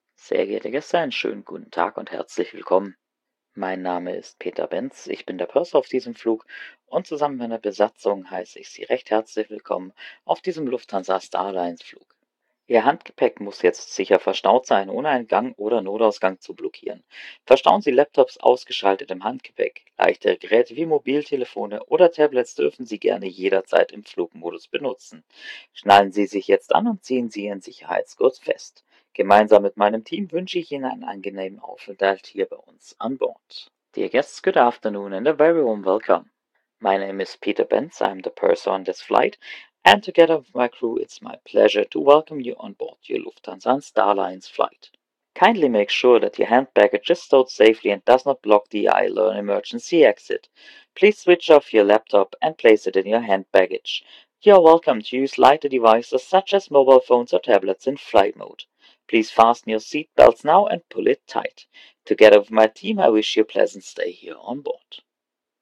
PreSafetyBriefing[Afternoon].ogg